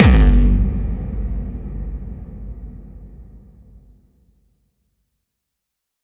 VEC3 FX Reverbkicks 12.wav